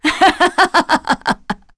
Seria-Vox_Happy3.wav